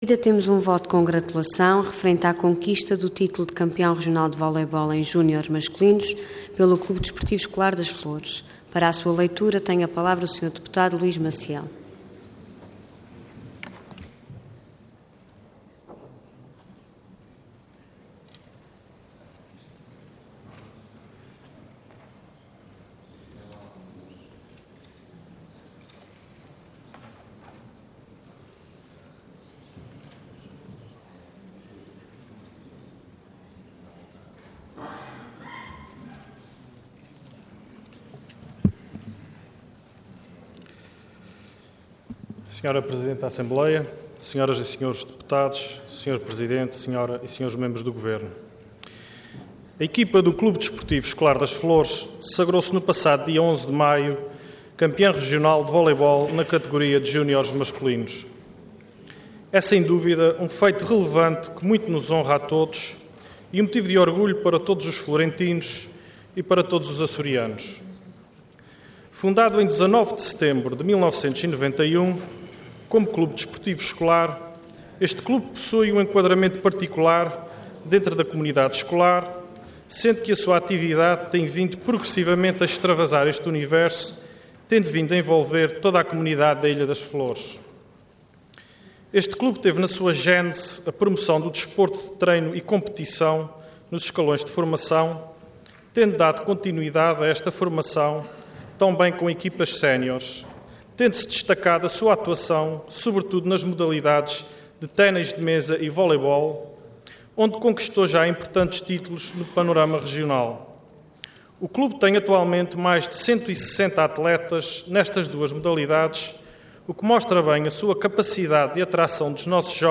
Intervenção Voto de Congratulação Orador Luís Maciel Cargo Deputado Entidade PS